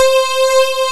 FUNK C6.wav